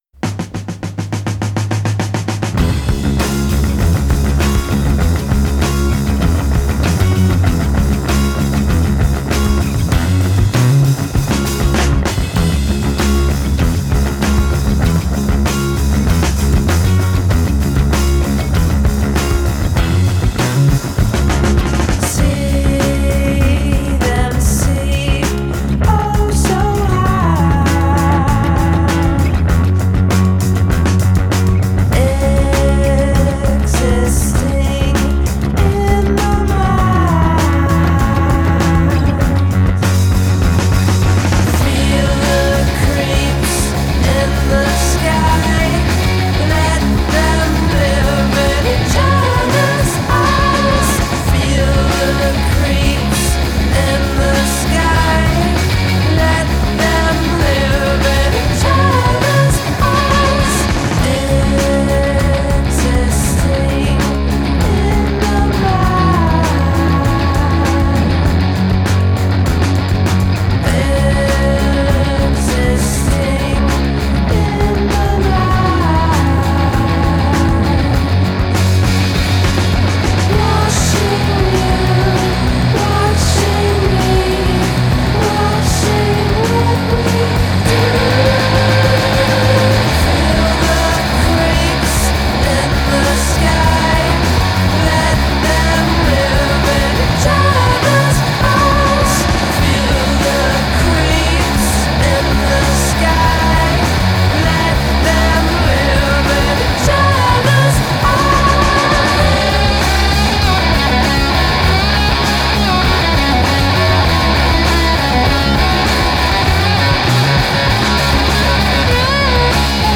raw, noisy garage rock